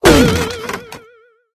wood_joint_break_03.ogg